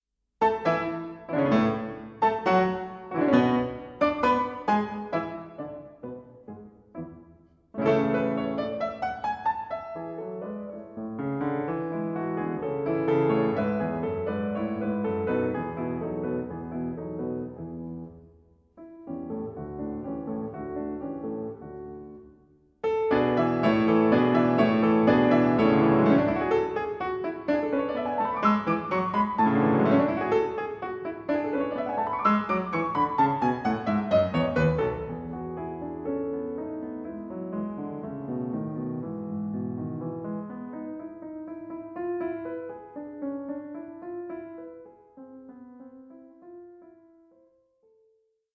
This is perhaps most noticeable in the subordinate theme, which reaches a more intense climax the second time around.
PIANO MUSIC